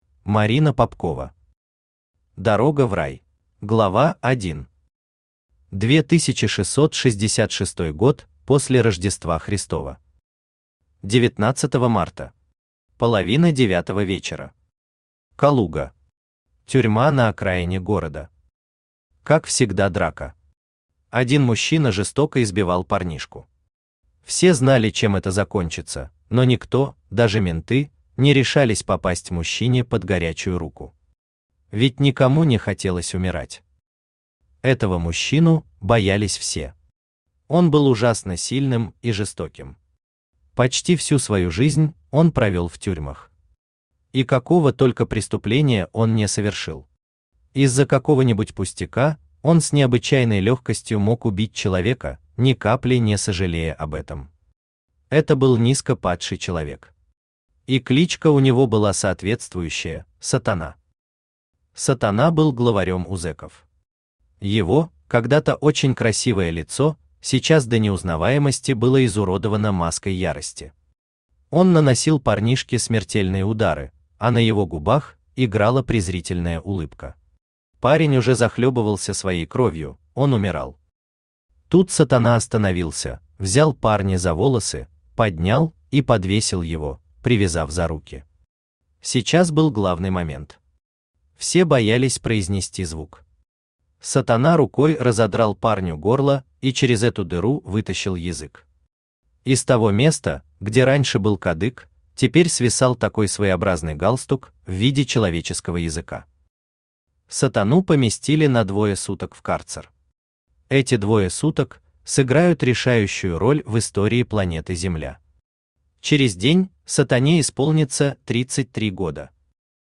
Аудиокнига Дорога в Рай | Библиотека аудиокниг
Aудиокнига Дорога в Рай Автор Марина Александровна Папкова Читает аудиокнигу Авточтец ЛитРес.